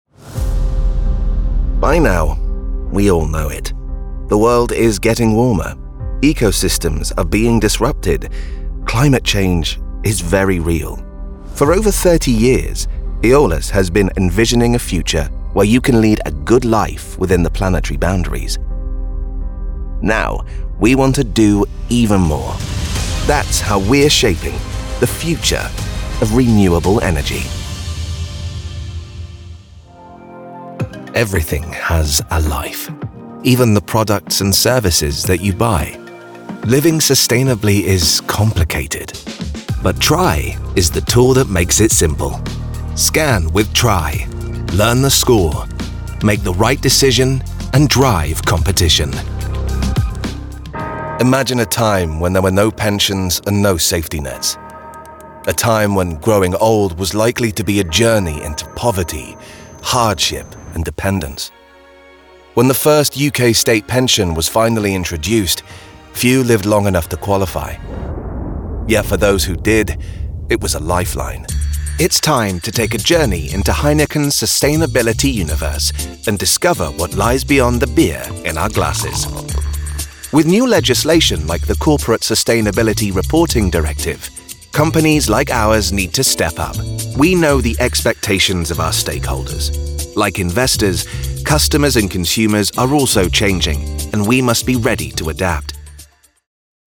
English (British)
Friendly, Commercial, Natural, Playful, Corporate
Corporate